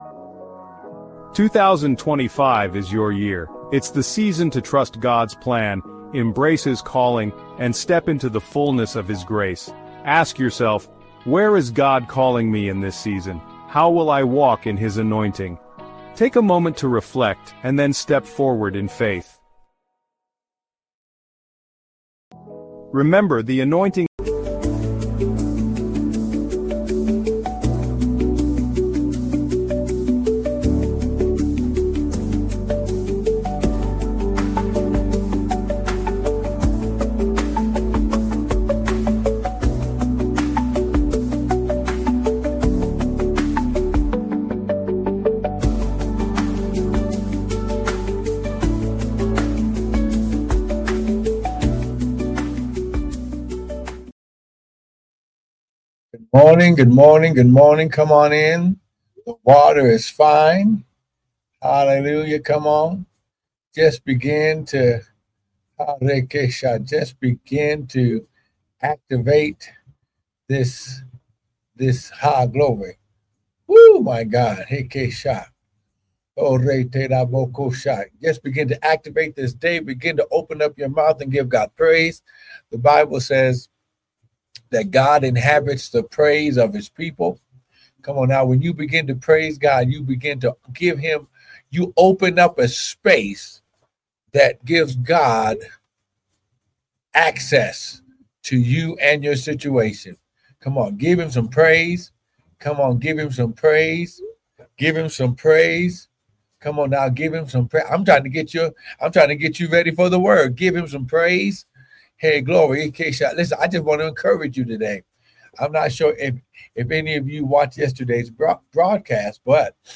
No signup or install needed. live-recording 6/20/2025 6:50:02 AM. live-recording 6/19/2025 8:33:06 AM.